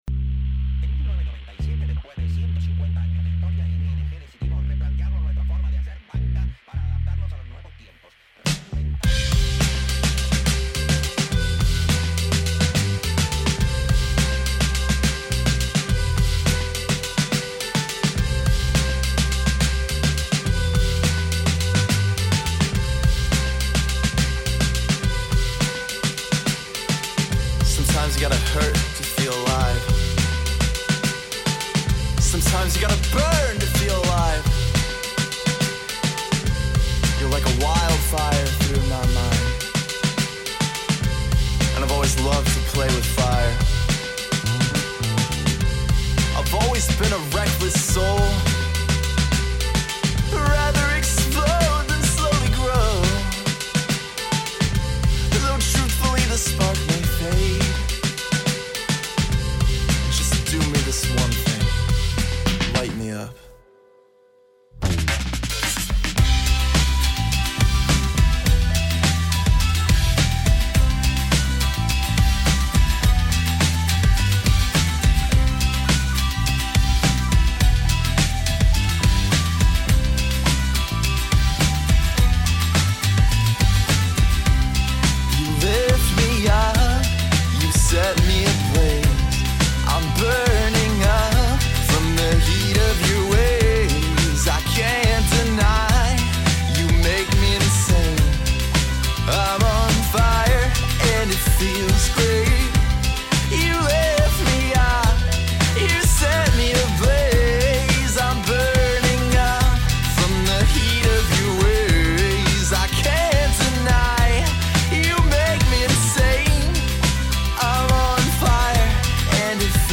A solo musical project
interview